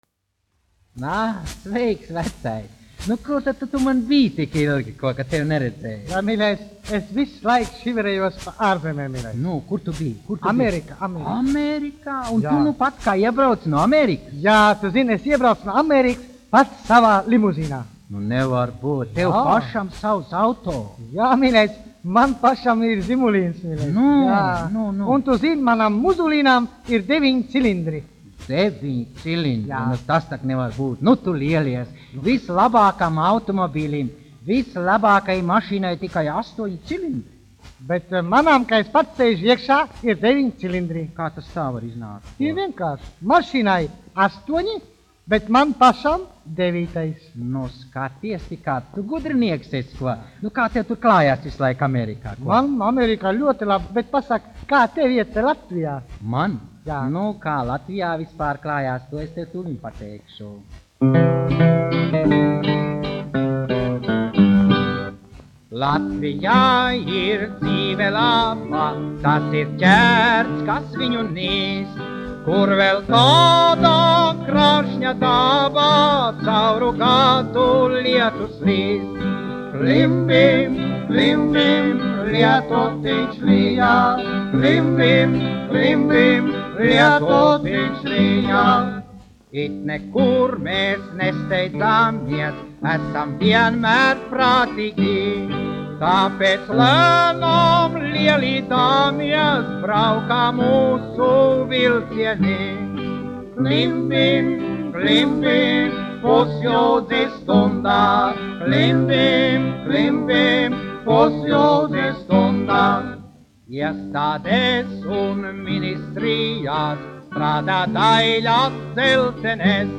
1 skpl. : analogs, 78 apgr/min, mono ; 25 cm
Humoristiskās dziesmas